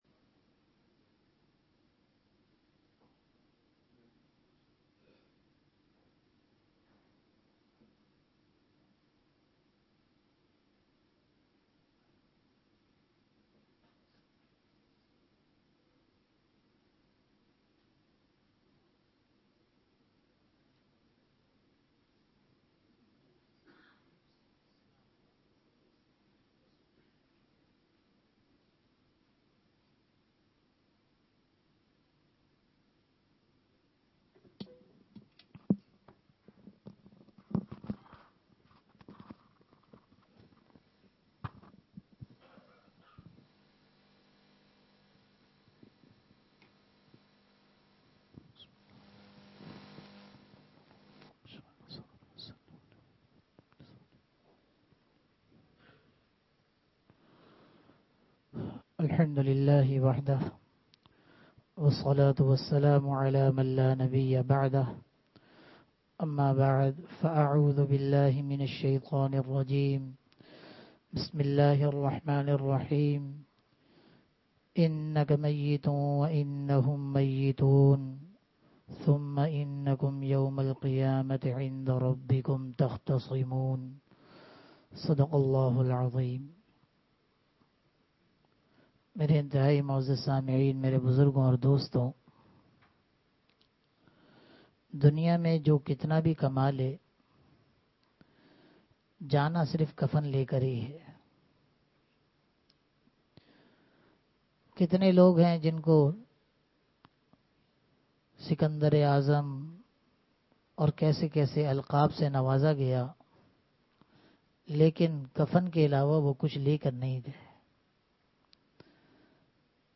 Bayanat
Bayanat (Jumma Aur Itwar)